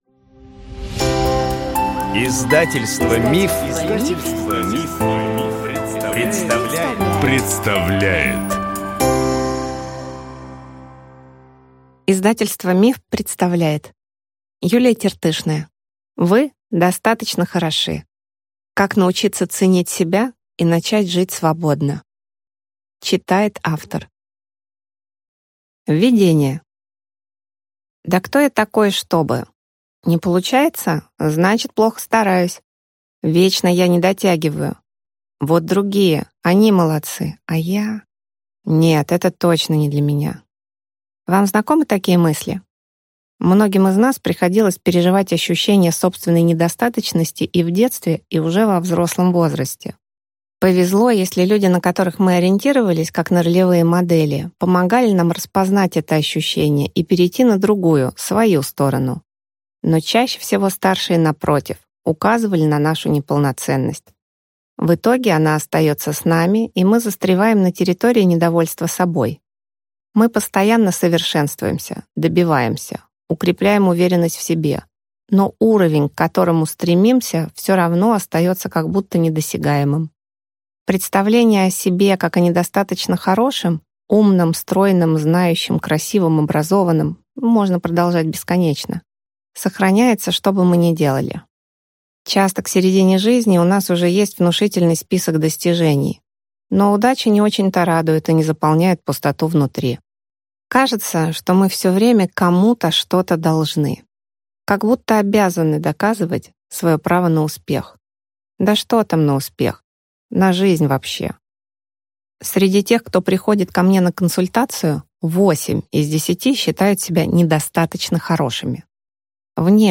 Аудиокнига Вы достаточно хороши. Как научиться ценить себя и начать жить свободно | Библиотека аудиокниг